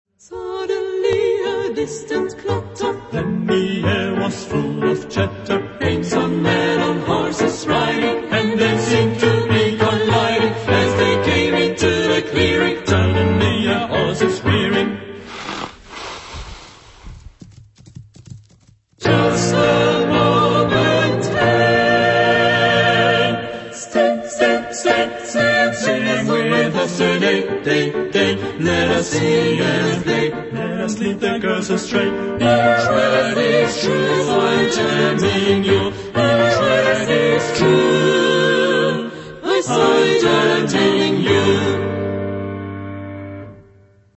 Genre-Style-Form: Secular ; Traditional
Mood of the piece: freely
Type of Choir: SATB  (4 mixed voices )
Soloist(s): Soprano (1) OR Ténor (1)  (1 soloist(s))
Instrumentation: Piano  (1 instrumental part(s))
Tonality: G minor
Origin: Russia